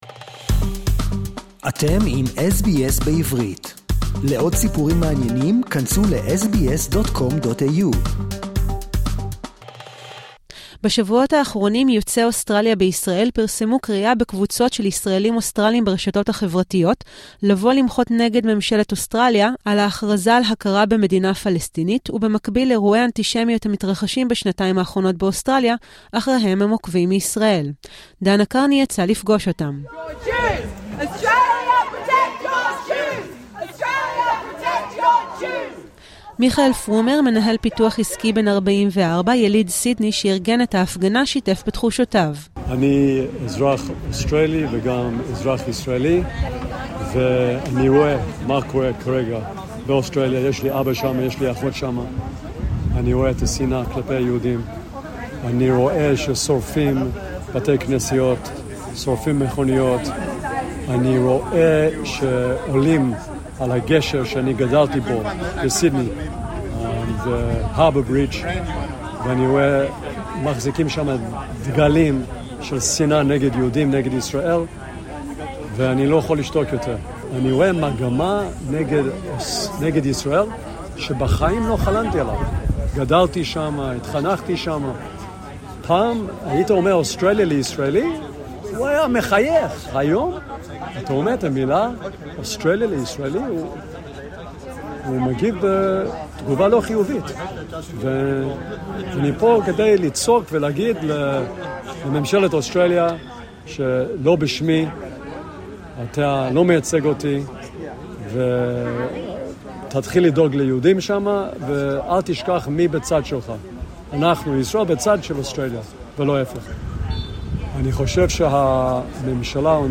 מפגינים יוצאי אוסטרליה בהפגנה ראשונה מסוגה מול בניין שגרירות אוסטרליה בתל אביב